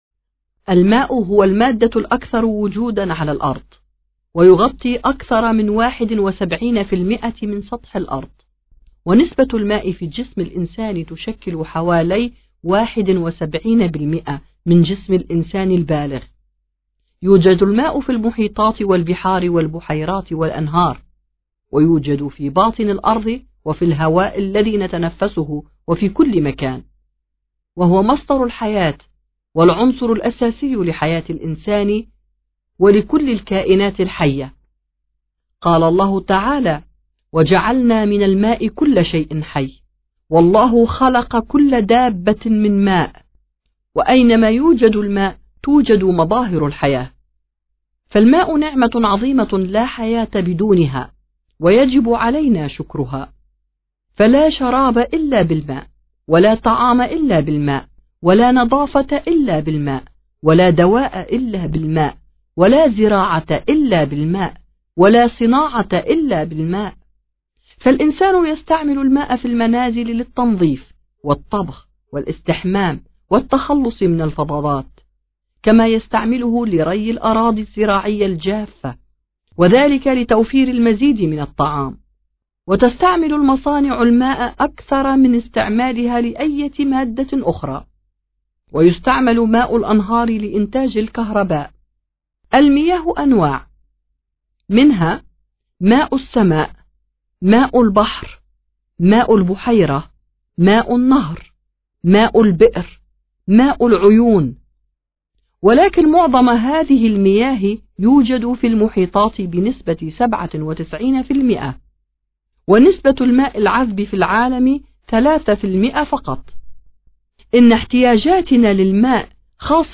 Su – Arapça Günlük Konuşmalar